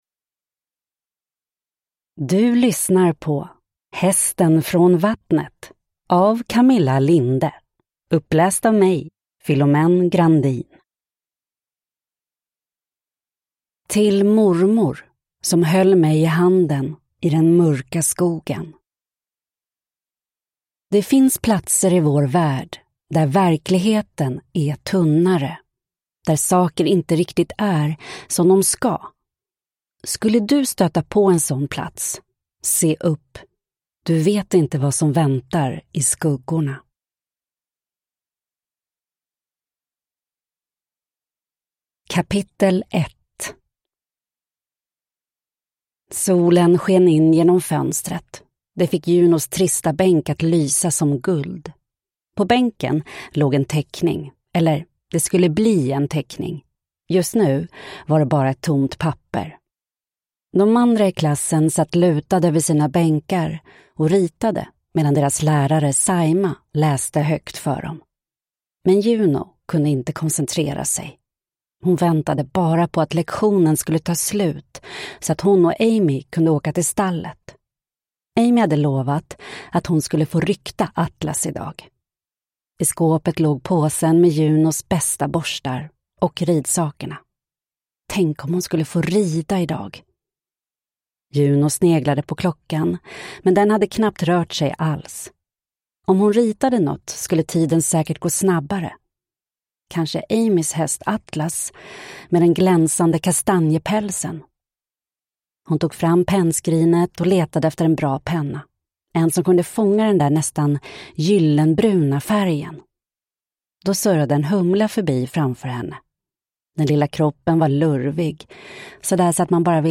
Hästen från vattnet – Ljudbok – Laddas ner